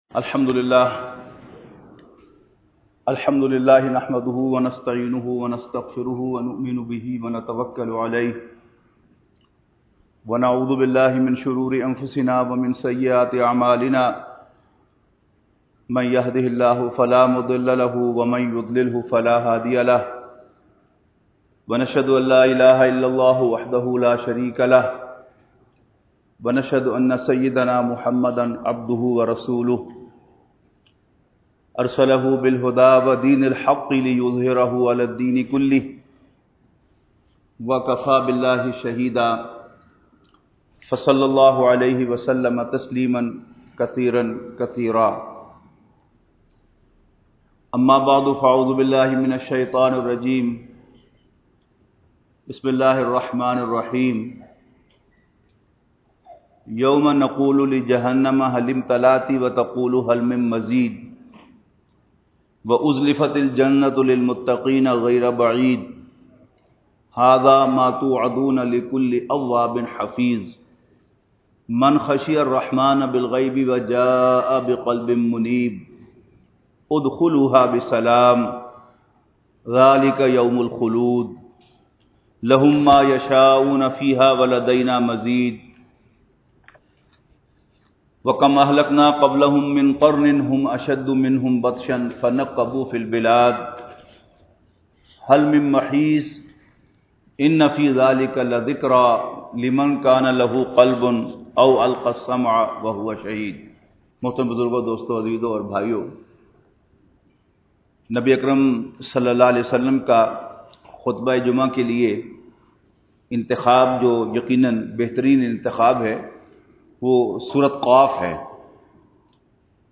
Khitab E Juma / Audio / Sura Qaf